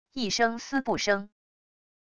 一声撕布声wav音频